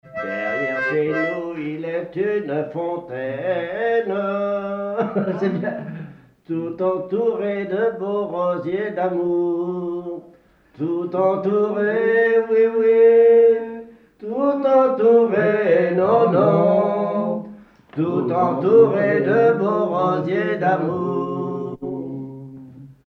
Localisation Verchaix
Pièce musicale inédite